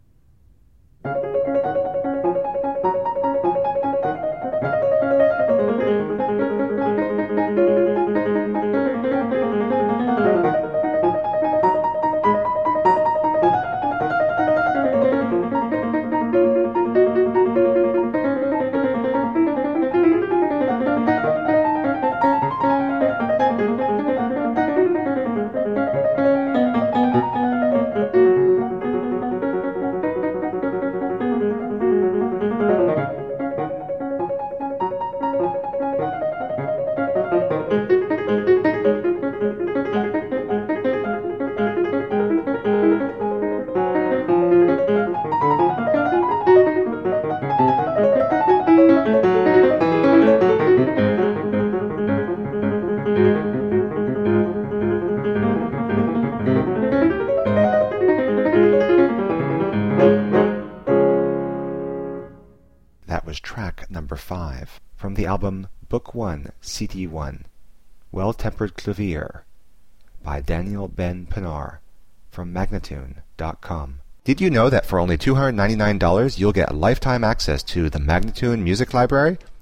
played with deep expressiveness and intelligence
solo piano music